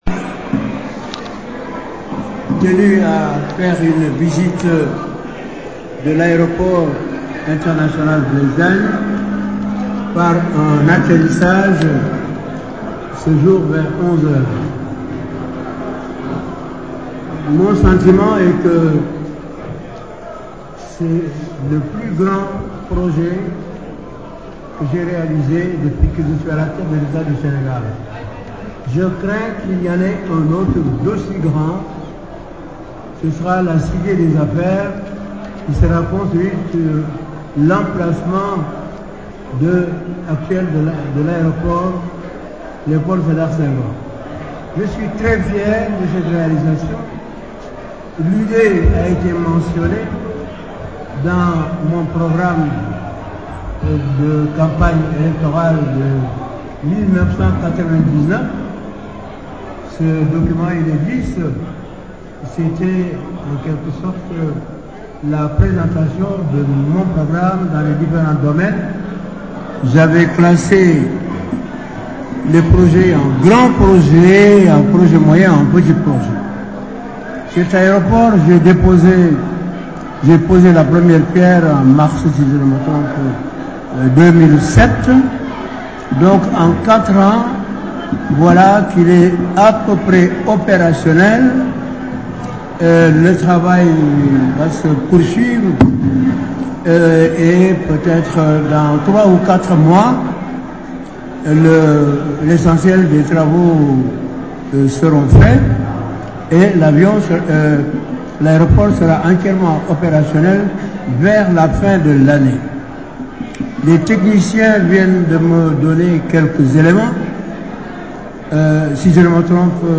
AIBD_ABdoualaye_Wade_Discours_fr.mp3 (2.24 Mo)